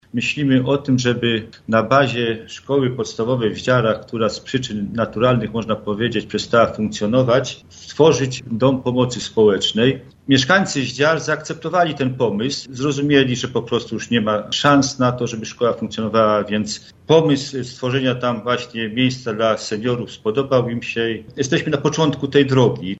O pomyśle na jego zagospodarowanie poinformował wójt Jarocina Zbigniew Walczak: